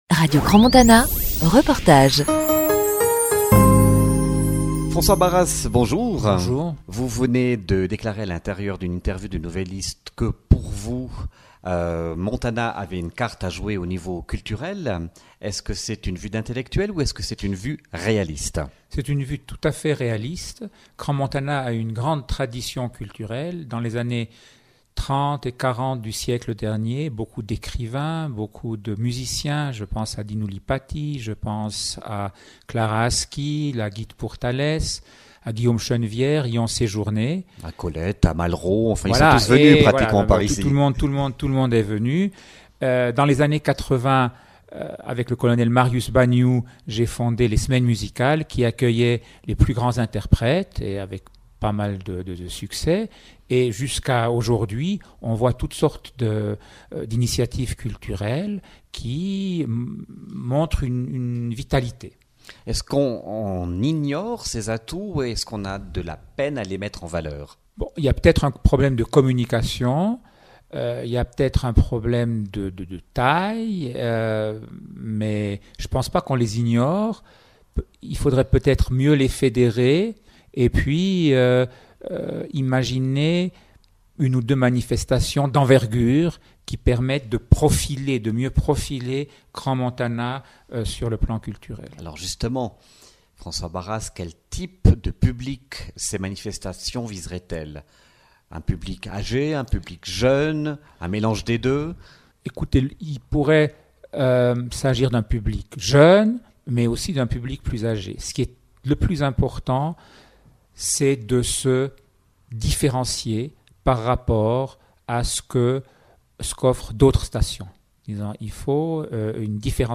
Interview de François Barras, Ambassadeur de Suisse au Liban (Partie 1 enregistrée le 9 janvier 2015)